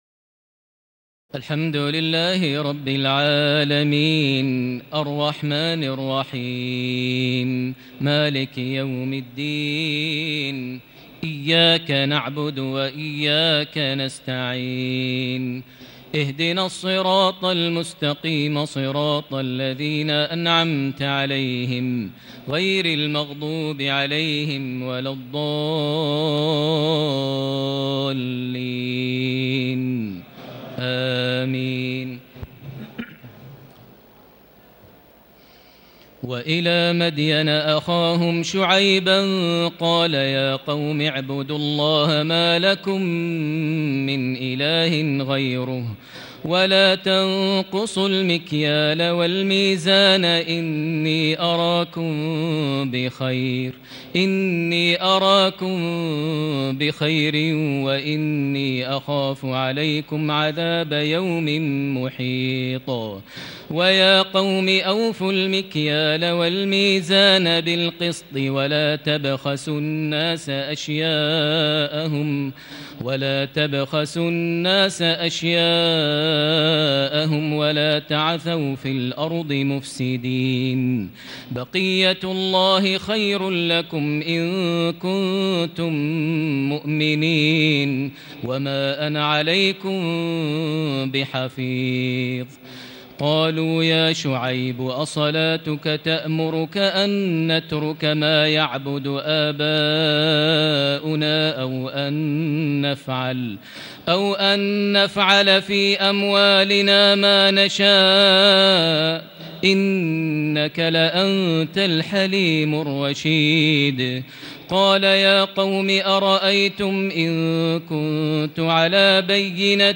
تراويح الليلة الحادية عشر رمضان 1440هـ من سورتي هود (84-123) و يوسف (1-57) Taraweeh 11 st night Ramadan 1440H from Surah Hud and Yusuf > تراويح الحرم المكي عام 1440 🕋 > التراويح - تلاوات الحرمين